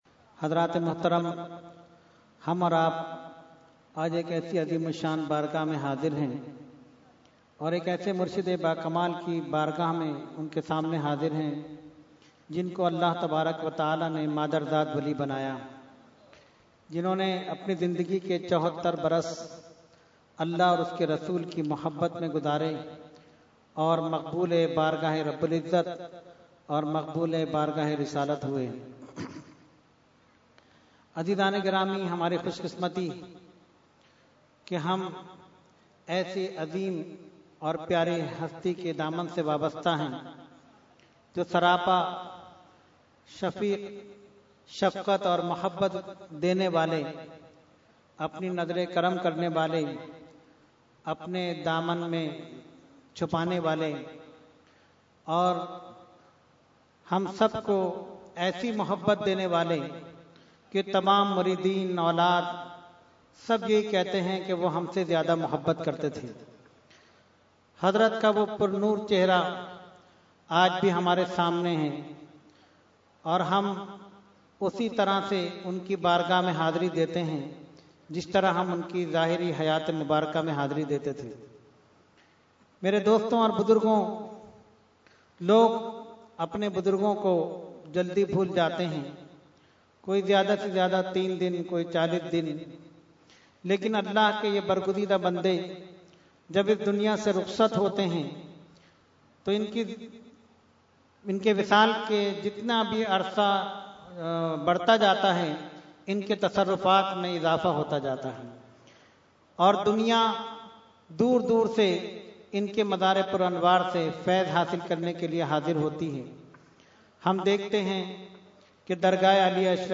Short Speech – Urs Ashraful Mashaikh 2016 – Dargah Alia Ashrafia Karachi Pakistan
Category : Speech | Language : UrduEvent : Urs Ashraful Mashaikh 2016